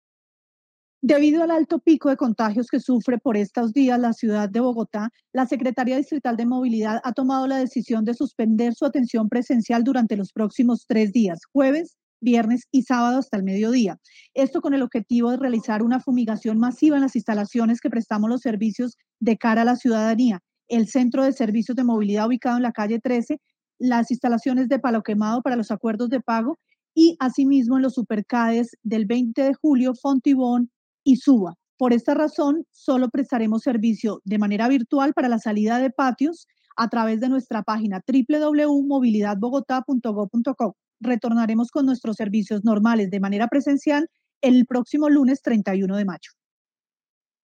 Declaración de la subsecretaría de Servicios a la Ciudadanía, Alejandra Moreno Gámez.